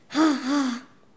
Les sons ont été découpés en morceaux exploitables. 2017-04-10 17:58:57 +02:00 204 KiB Raw Permalink History
haha_02.wav